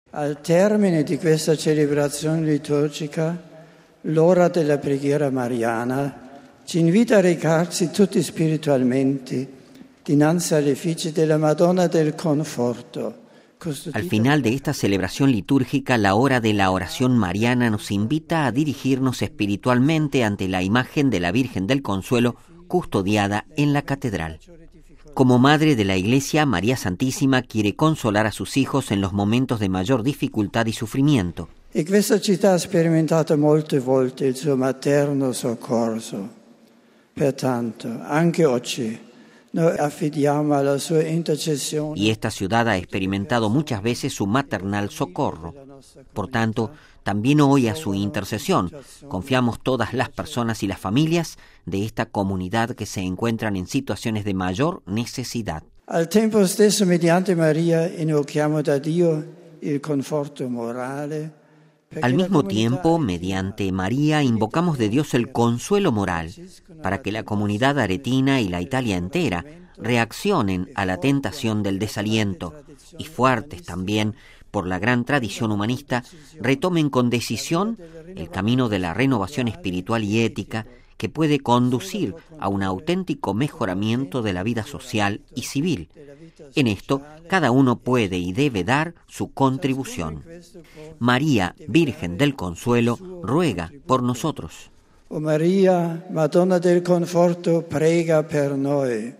TEXTO ALOCUCIÓN DEL PAPA A LA HORA DEL REGINA COELI (Audio) RealAudio